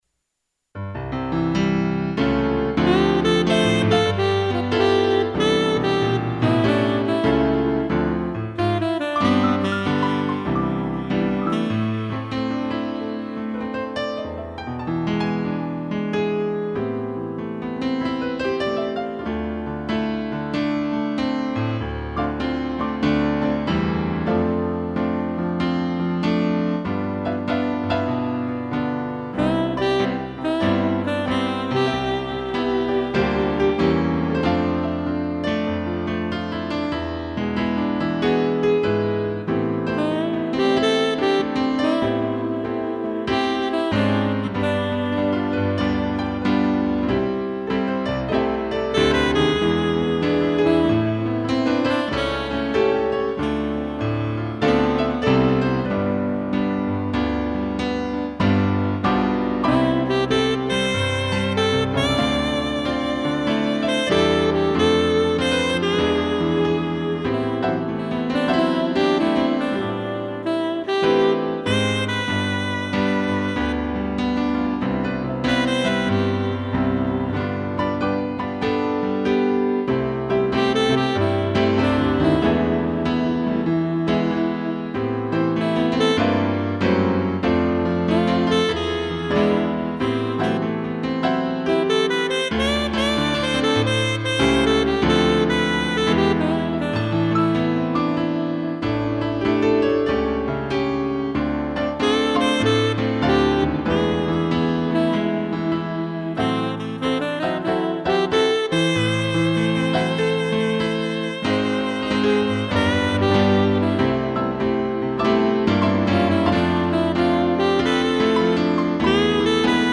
2 pianos e sax